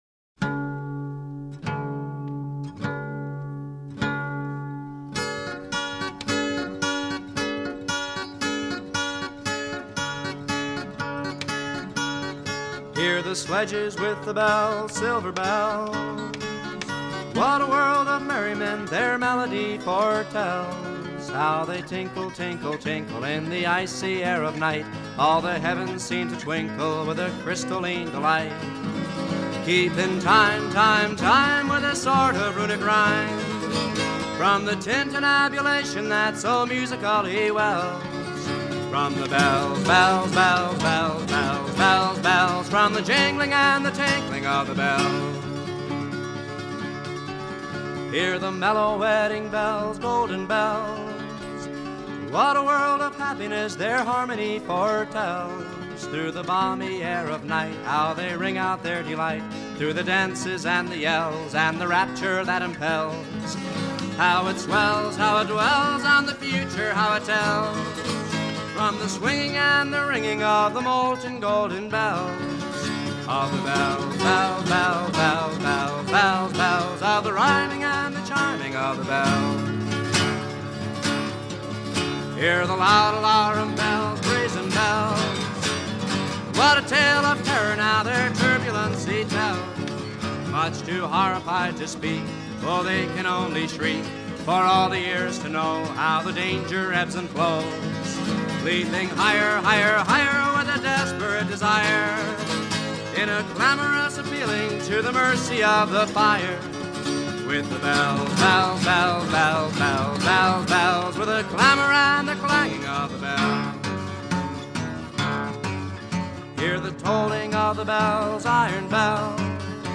slightly syncopated musical adaptation